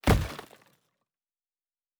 Fantasy Interface Sounds
Stone 06.wav